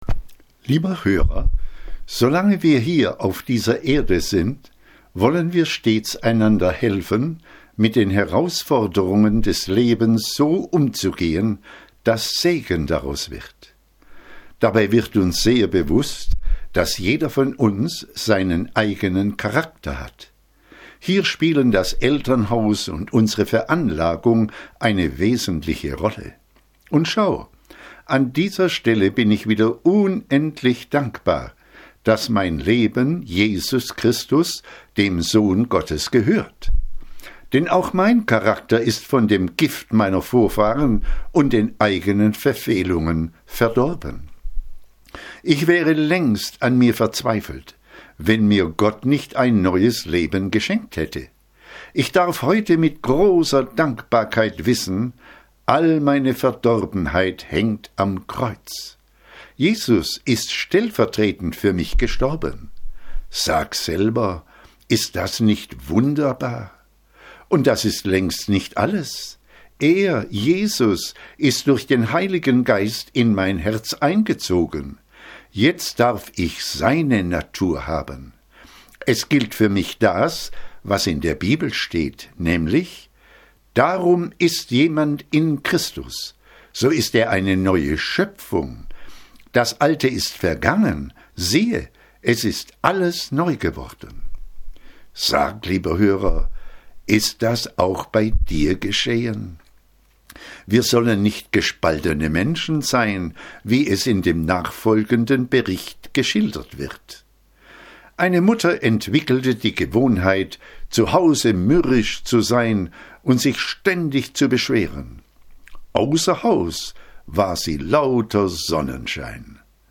Letzte Predigten